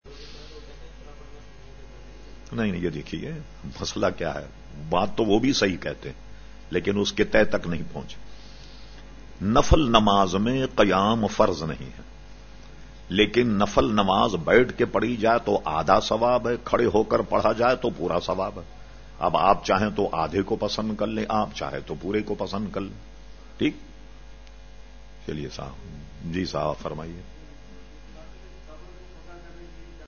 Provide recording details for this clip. Q/A Program held on Sunday 26 February 2012 at Masjid Habib Karachi.